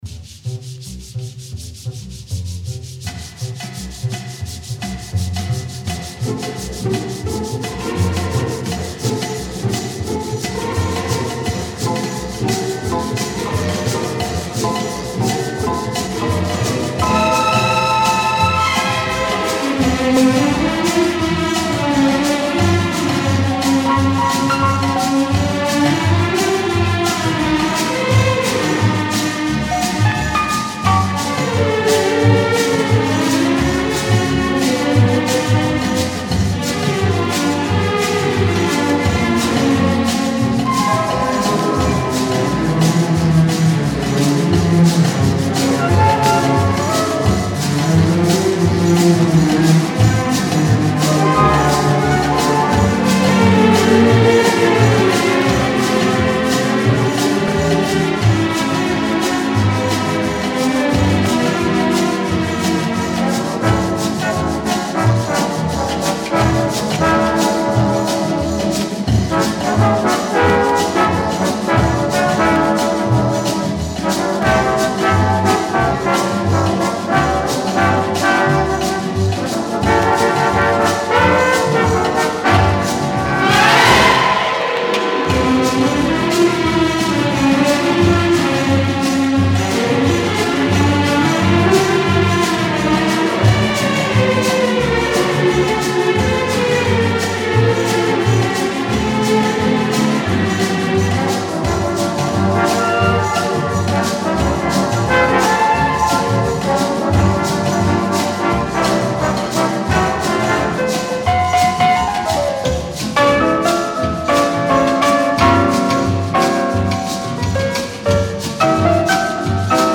Genres:Easy Listening